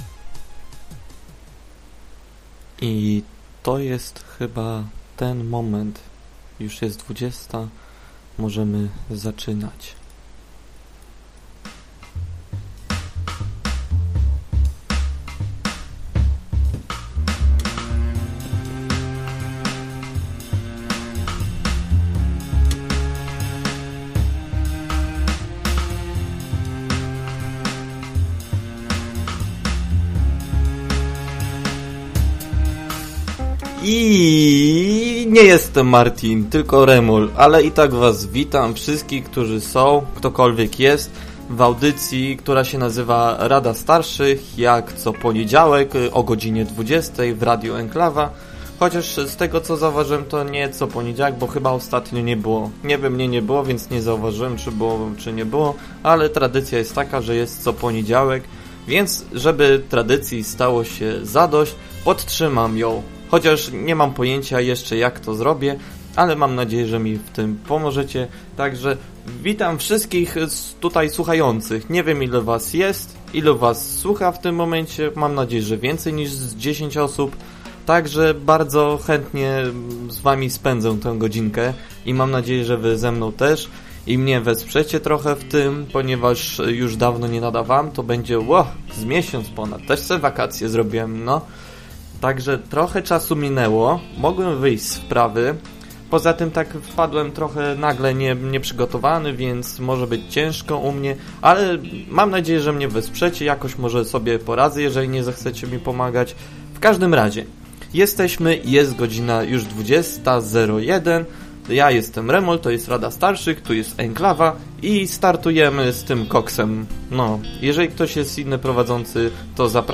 Rozmawiał ze słuchaczami na temat co pić i jak pić.